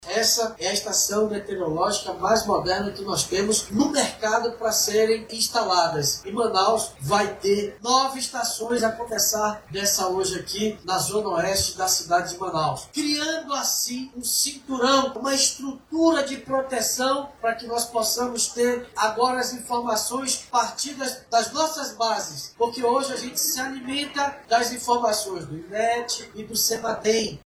As estações vão possibilitar à Prefeitura de Manaus, o planejamento de ações de preventivas e de emergência frente aos eventos climáticos, explica o prefeito Davi Almeida.